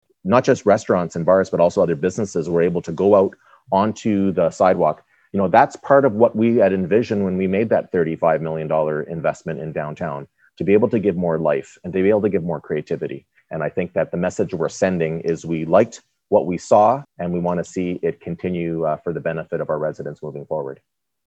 Mayor Mitch Panciuk says the new framework helps build on momentum gained by local business owners last summer, especially those in the Downtown District.